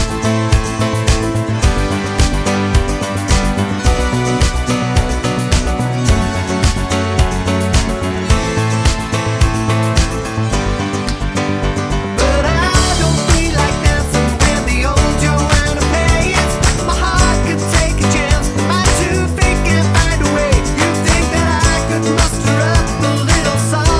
With Intro Two Semitones Down Pop (2000s) 4:26 Buy £1.50